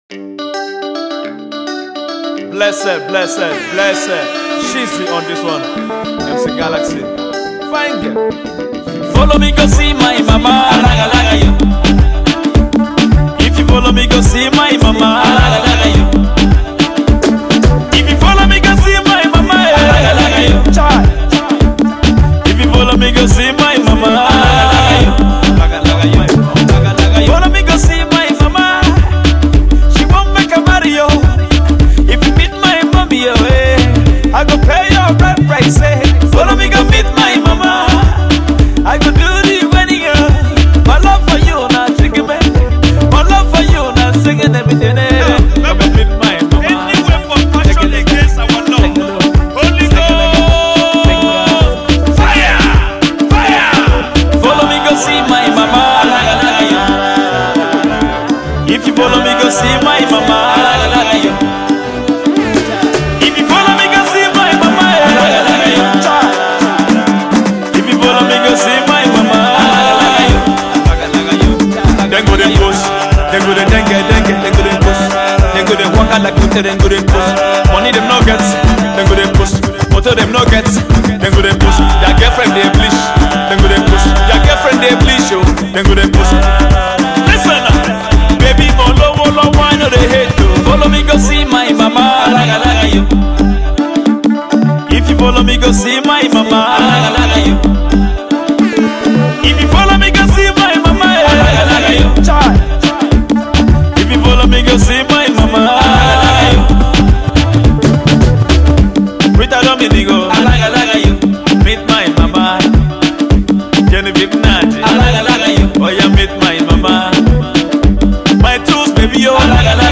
they were more or less inspired by 80’s Pop Music